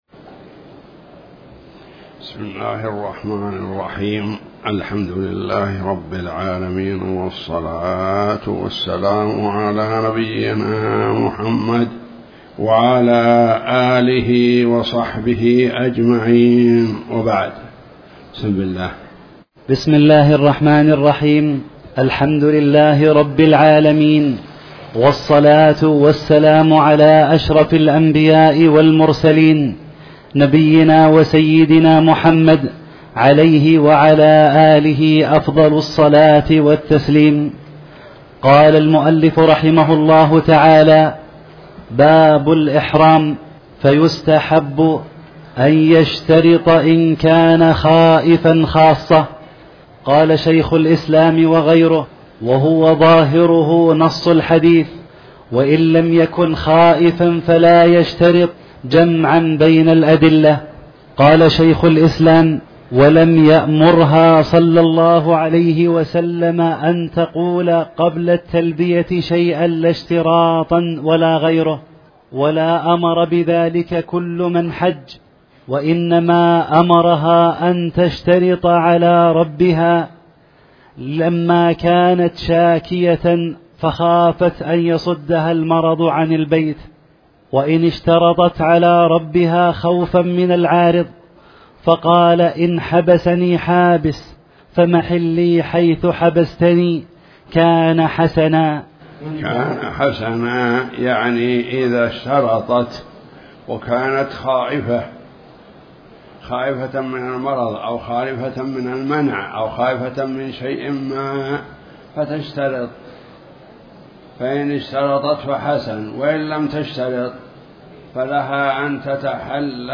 تاريخ النشر ٢٩ ذو الحجة ١٤٣٩ هـ المكان: المسجد الحرام الشيخ